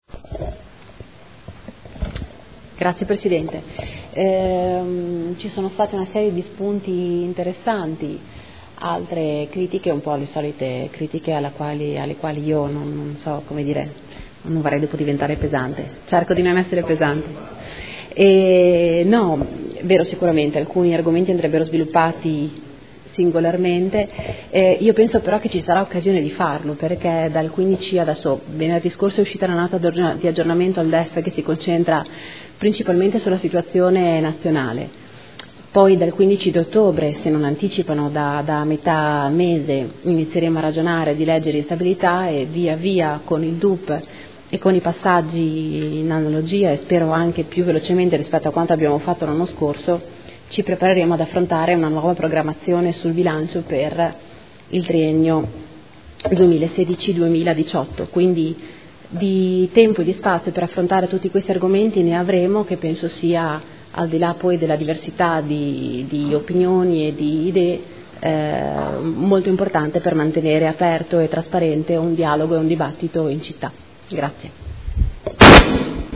Seduta del 21/09/2015.
Replica